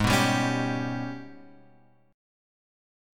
G# Minor Major 11th